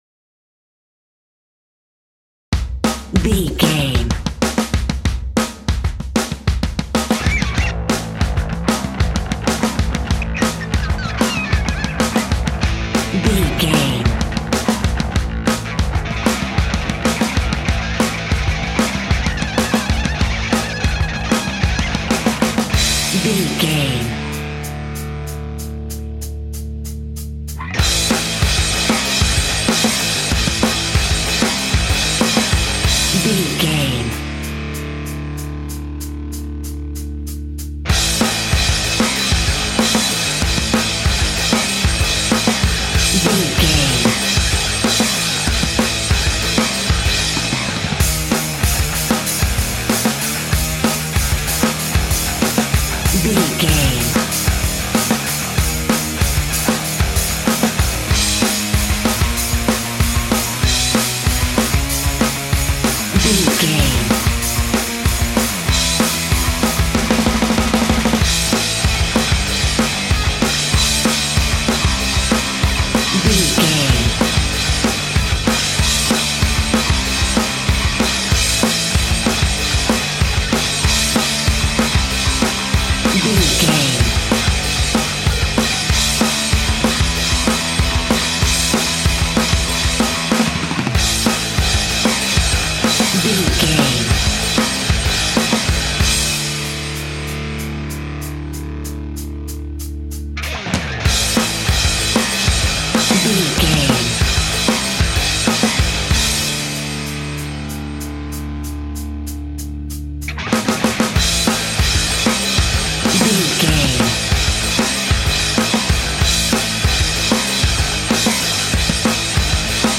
Fast paced
Aeolian/Minor
Fast
hard rock
punk metal
instrumentals
Rock Bass
heavy drums
distorted guitars
hammond organ